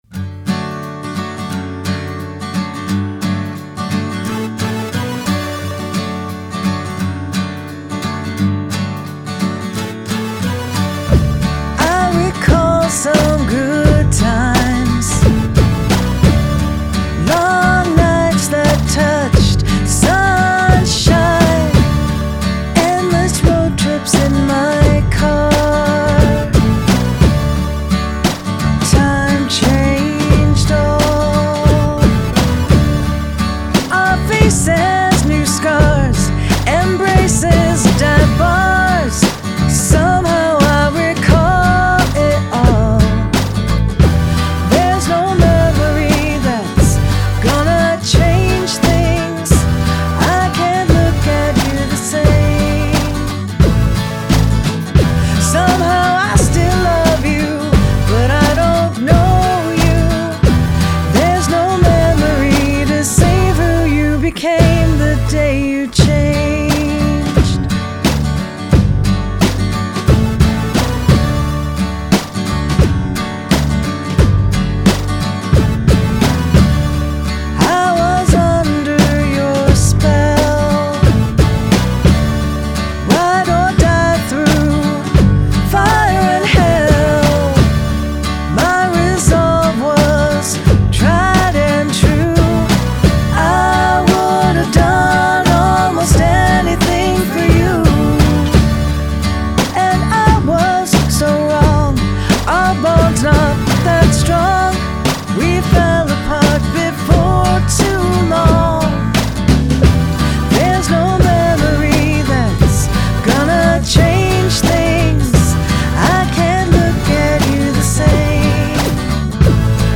Elements of chiptune/ computer sounds